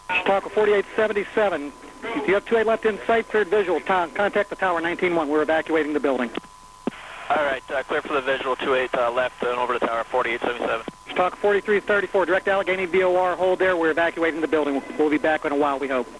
0949 EDT Evacuation announcement.